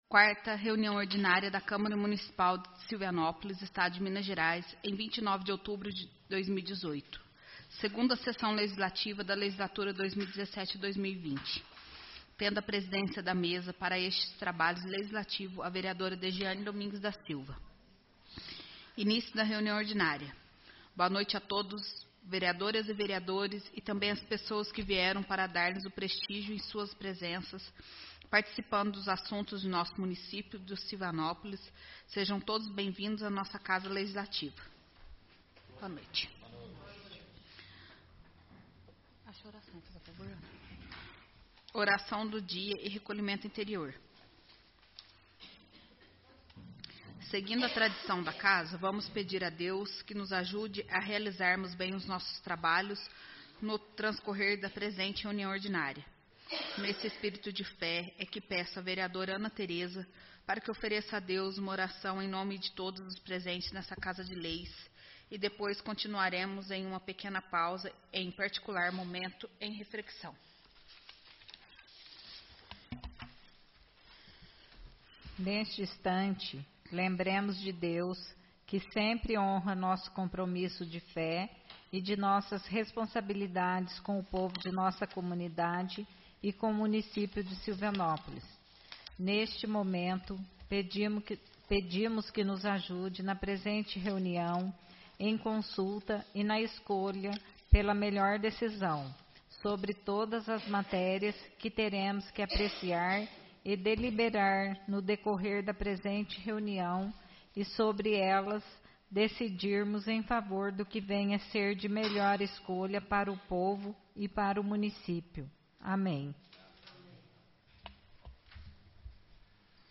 Áudio da 34ª Reunião Ordinária